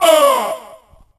8bit_hurt_vo_02.ogg